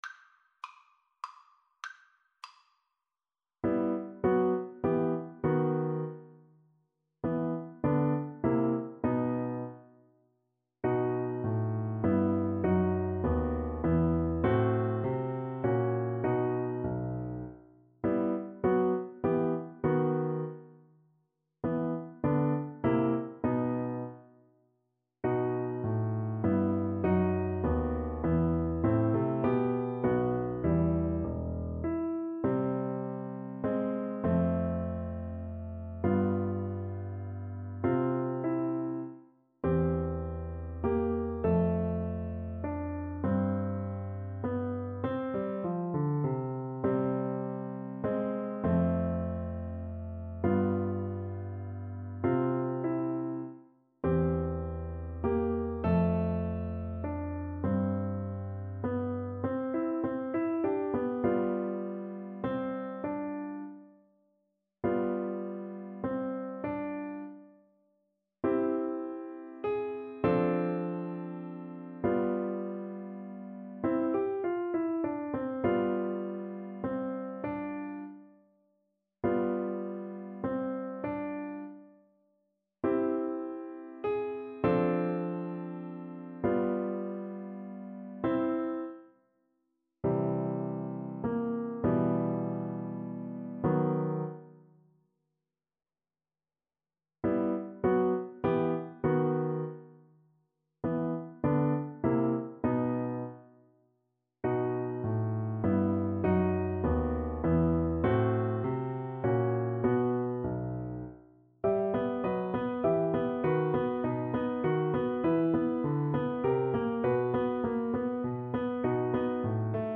Play (or use space bar on your keyboard) Pause Music Playalong - Piano Accompaniment Playalong Band Accompaniment not yet available transpose reset tempo print settings full screen
3/4 (View more 3/4 Music)
E minor (Sounding Pitch) (View more E minor Music for Cello )
Allegretto
Classical (View more Classical Cello Music)